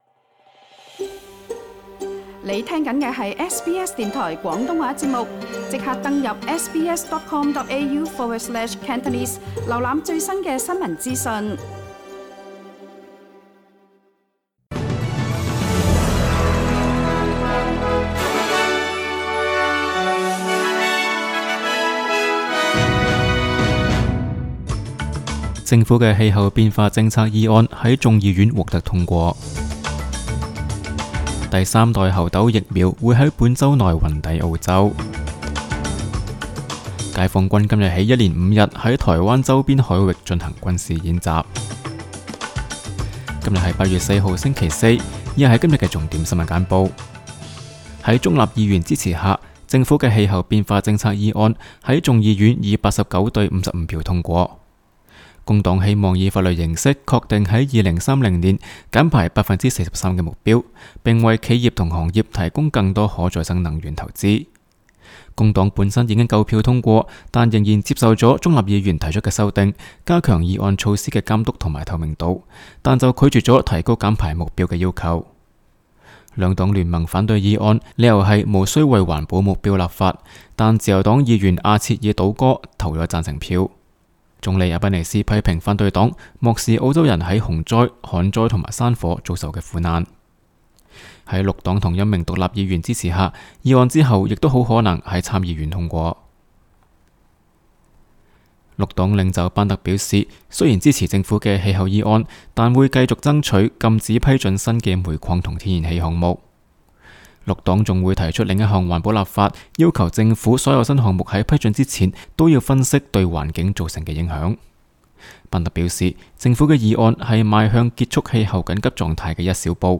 SBS 新聞簡報（8月4日）